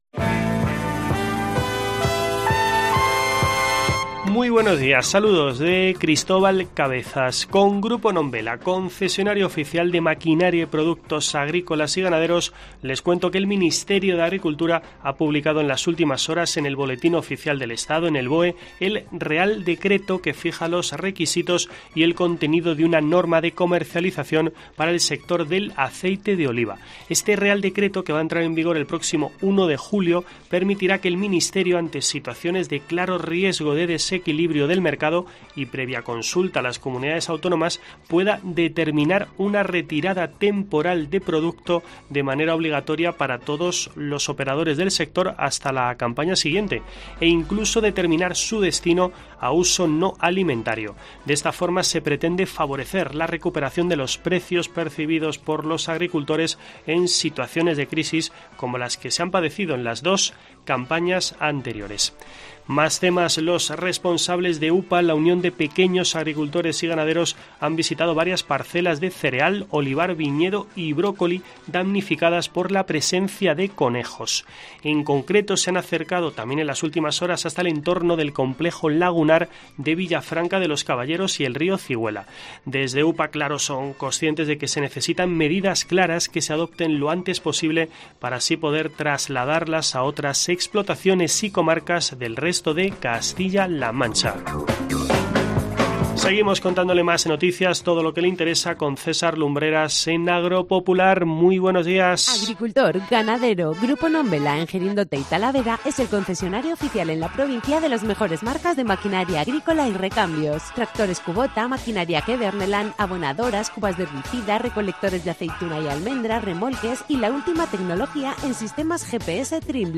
Escucha en la parte superior de esta noticia toda la actualidad del mundo del campo en nuestro boletín informativo semanal.